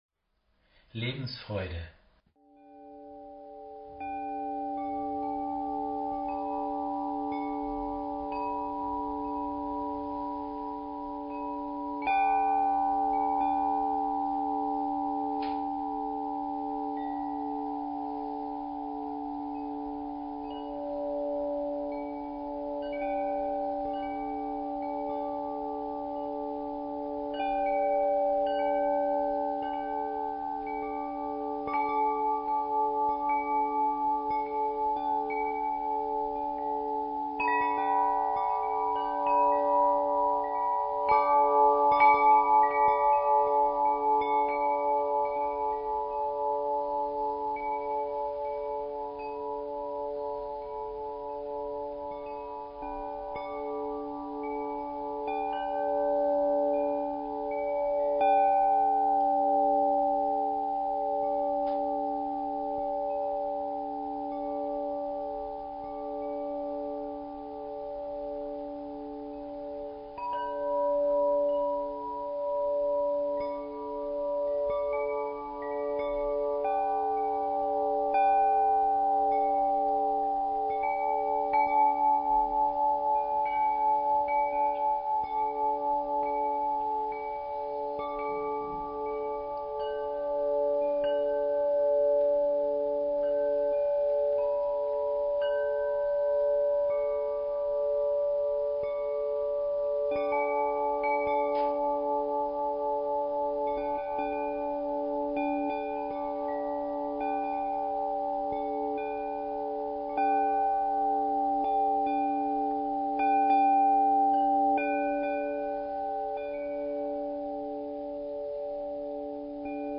Klangspiel-Komposition